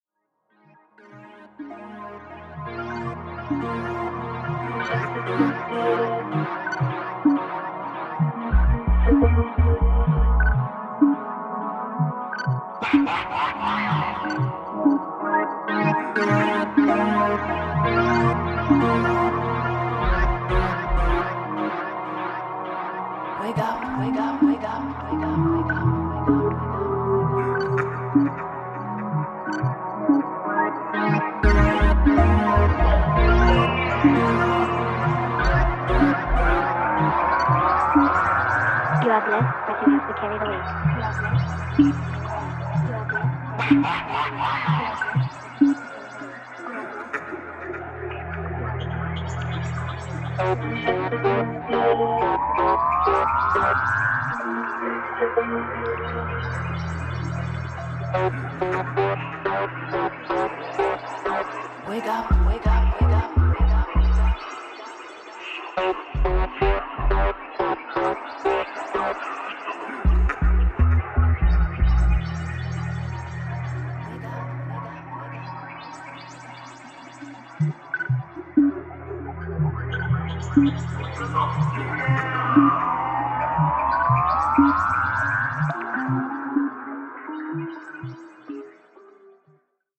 Electro House Techno Minimal